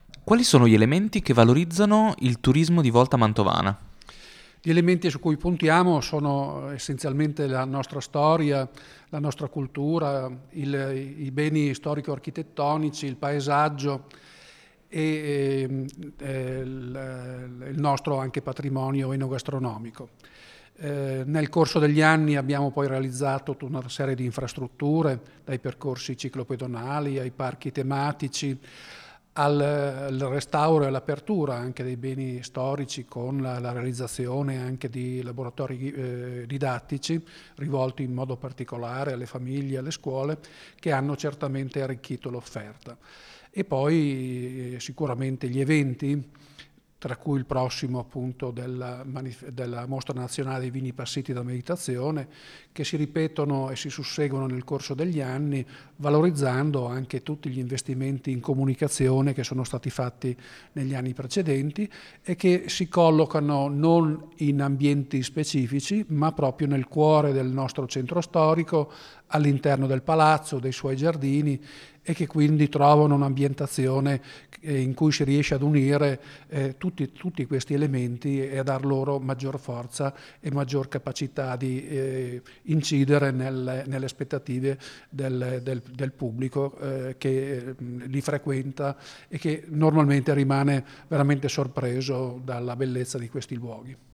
Ecco, ai nostri microfoni, le dichiarazioni raccolte durante la serata:
Luciano Bertaiola, sindaco di Volta Mantovana